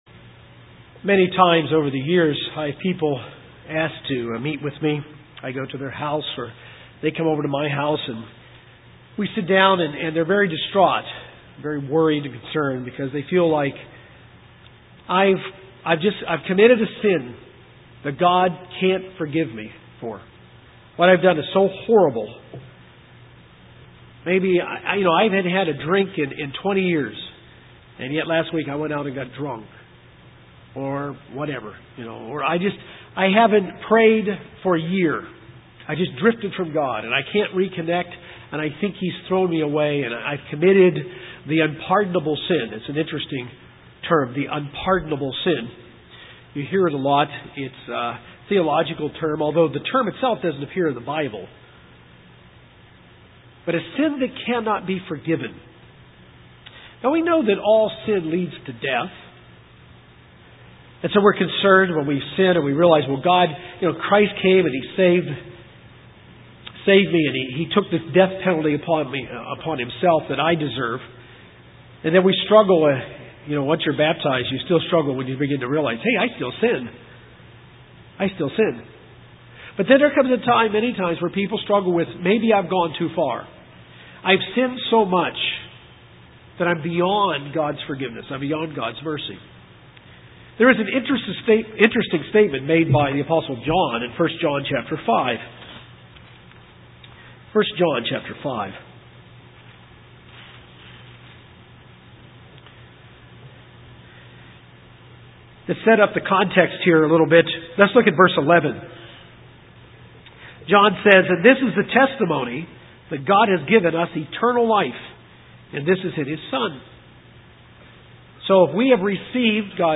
This sermon gives a better understanding of the sin that leads to the second death.